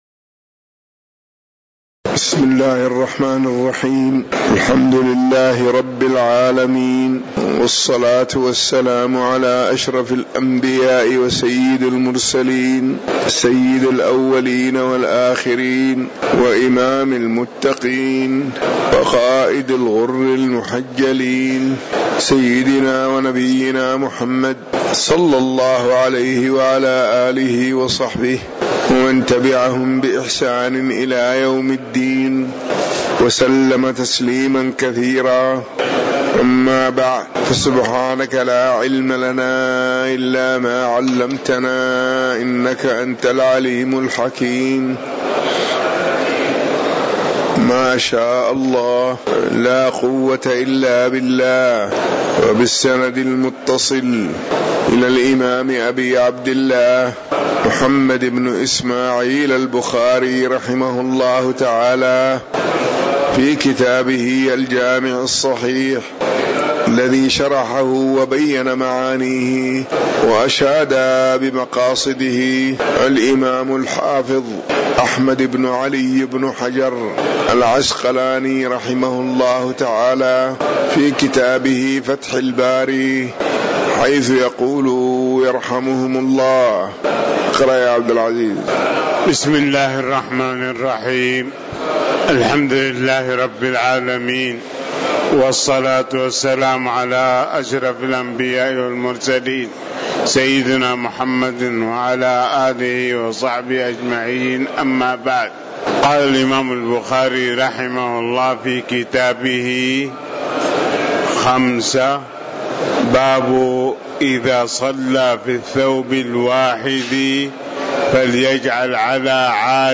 تاريخ النشر ٤ شعبان ١٤٤٠ هـ المكان: المسجد النبوي الشيخ